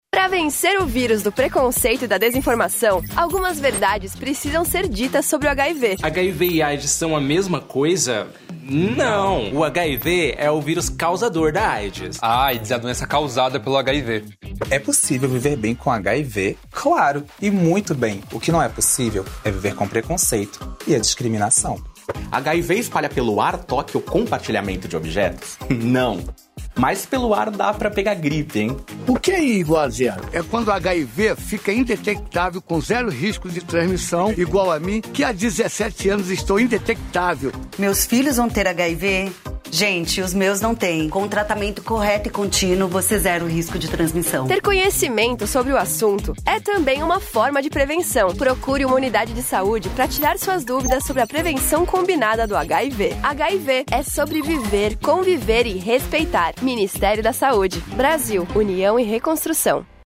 Spot Ministério da Saúde - Campanha dengue e chikungunya